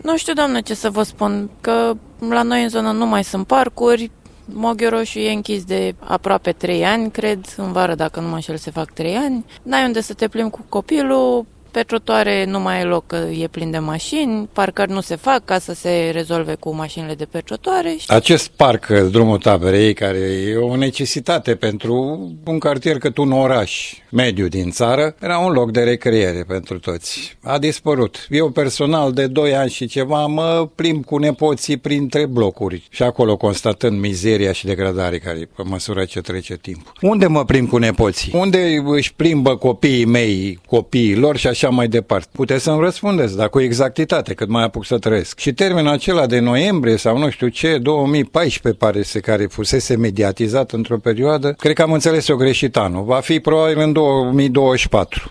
vox-parc-18-feb.mp3